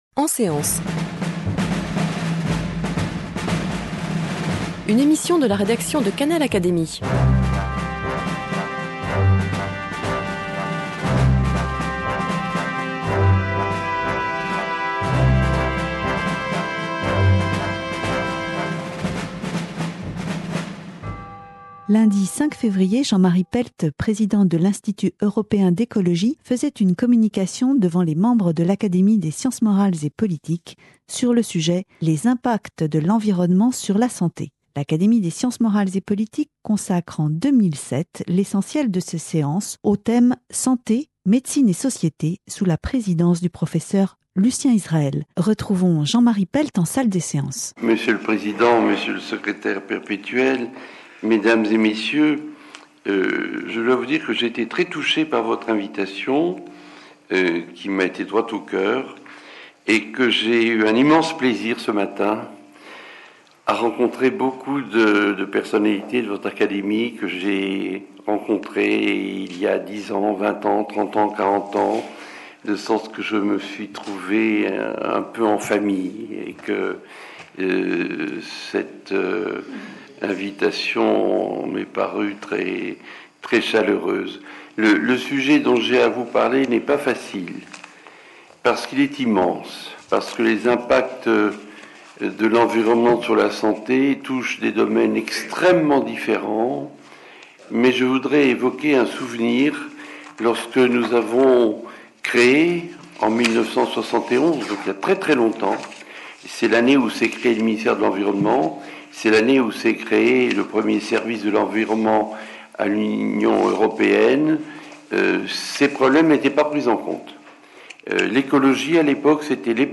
Ecoutez la communicaton de Jean-Marie Pelt devant l'Académie des sciences morales et politiques le 5 février 2007.